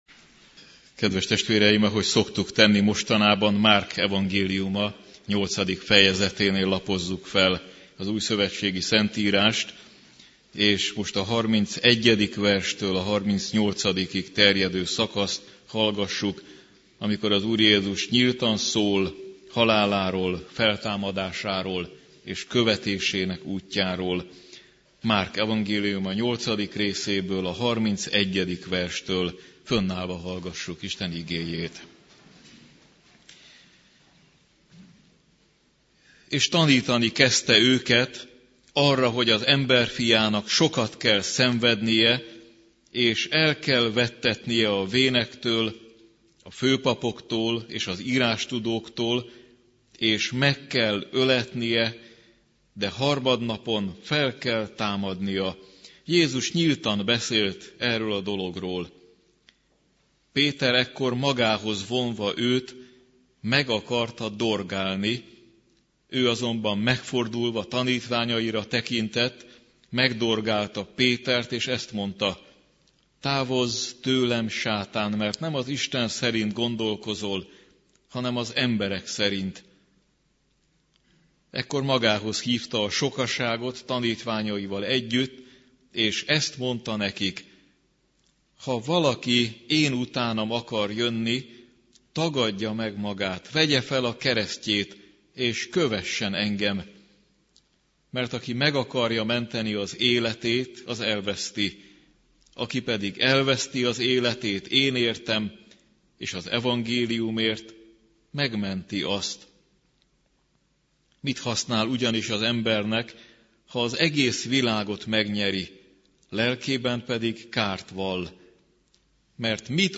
- Igehirdet�sek - hangfelv�tel 2009-2012